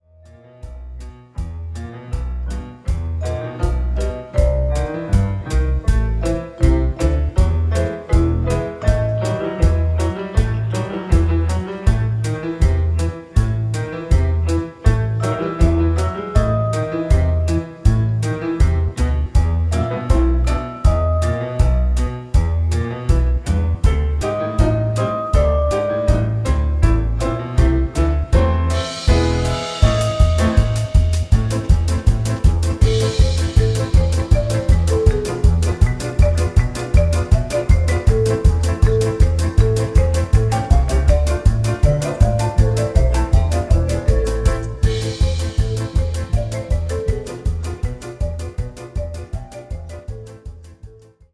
fade out à 3'06